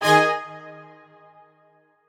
admin-leaf-alice-in-misanthrope/strings34_2_013.ogg at a8990f1ad740036f9d250f3aceaad8c816b20b54